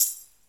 normal-slidertick.ogg